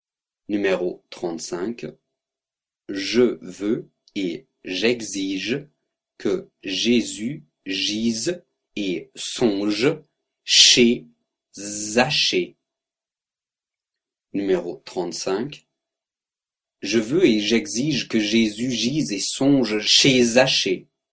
35 Virelangue